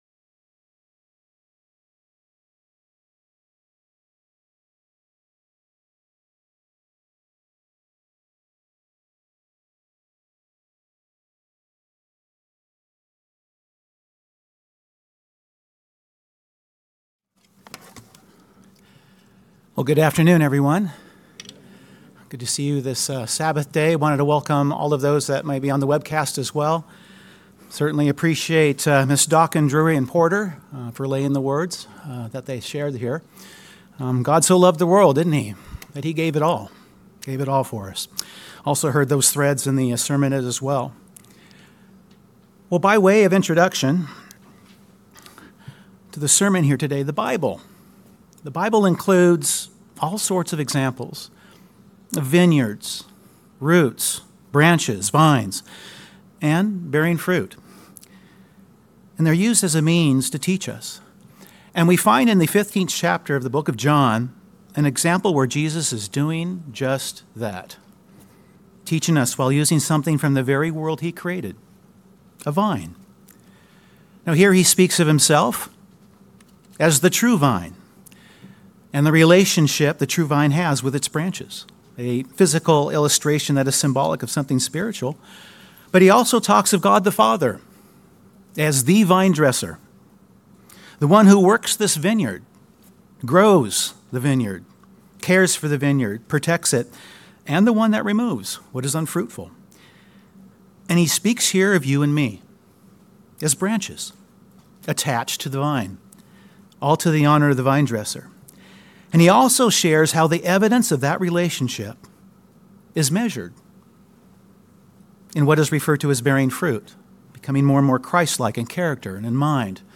Scripture speaks about vines, about a vinedresser, about branches and about bearing fruit. This sermon will touch on all of these with the understanding that there is a True Vine (Jesus Christ), a Vinedresser (God the Father), there is a place for us to abide spiritually, and that there is an attachment that must take place, of the branch to the vine, in order for us to bear the fruit God refers to in John 15.